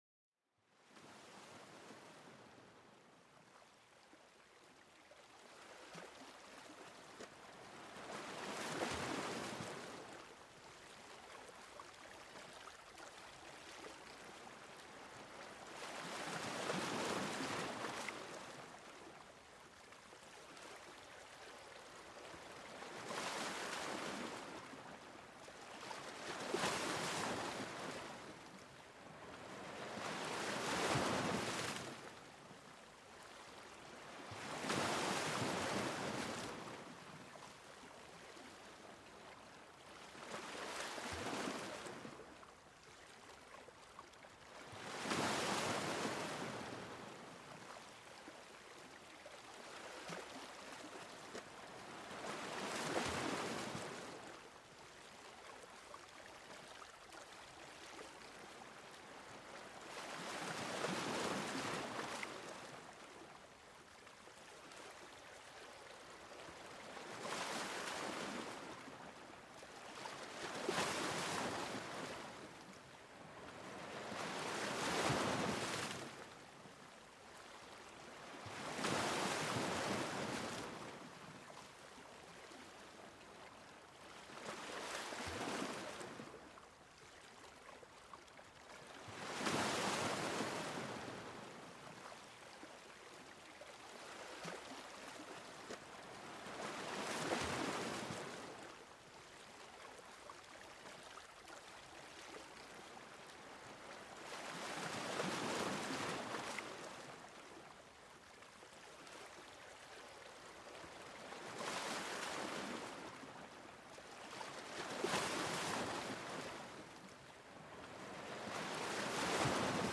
Son des Vagues de l'Océan pour une Relaxation Profonde
Plongez dans la sérénité des vagues de l'océan avec cet épisode apaisant. Écoutez le doux murmure de l'eau et laissez-vous emporter par le calme marin. Parfait pour se détendre, méditer ou s'endormir paisiblement.Ce podcast vous offre une évasion auditive au cœur de la nature.